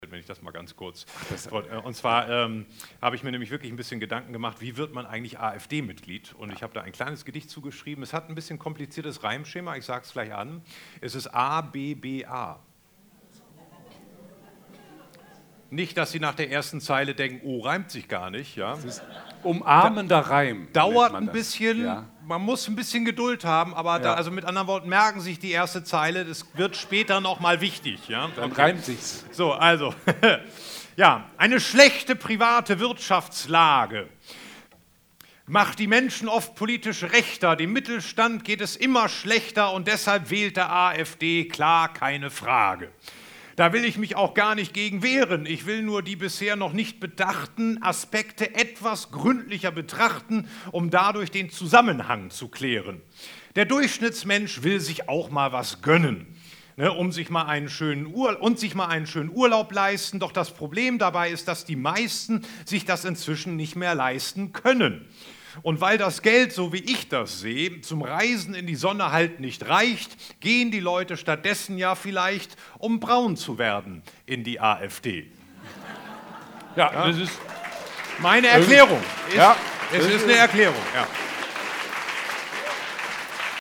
Events, Live-Übertragungen
Es wurde musiziert, Geschichten vorgelesen und Gedichte vorgetragen.
BENEFIZ-COMEDY-MIXED-SHOW